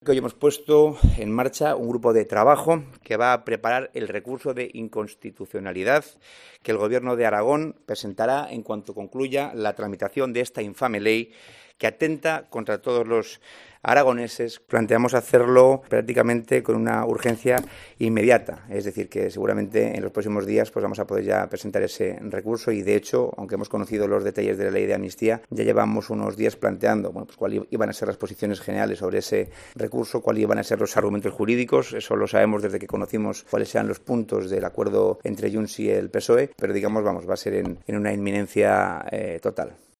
El vicepresidente Alejandro Nolasco, anuncia la presentación de un recurso de inconstitucionalidad
Una ley que según ha apuntado Nolasco basado en las opiniones de la judicatura y Guardia Civil "atenta" contra toda la ciudadanía y supone un "ataque frontal" a la independencia de poderes y a la propia Constitución, como ha asegurado en una comparecencia ante los medios en la que ha acusado a Pedro Sánchez de ser un presidente "ilegítimo" cuando llegó a la Moncloa por primera vez y ahora "ilegal".